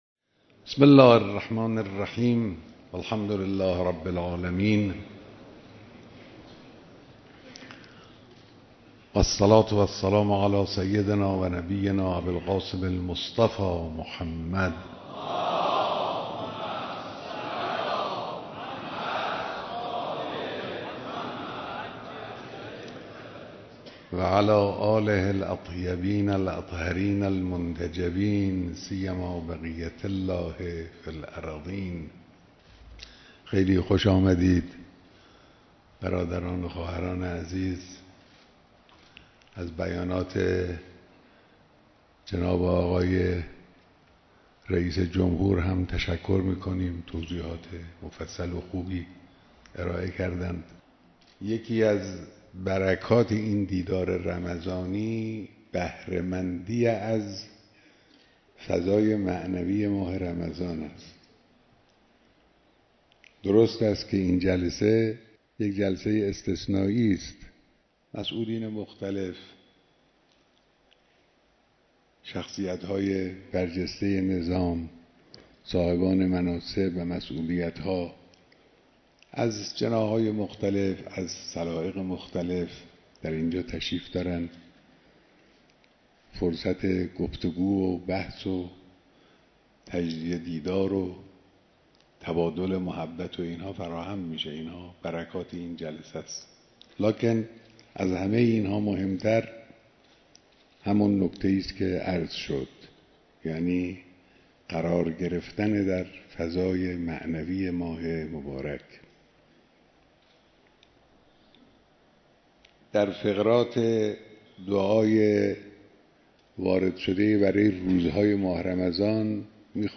بیانات در دیدار مسئولان و کارگزاران نظام